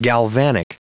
Prononciation du mot galvanic en anglais (fichier audio)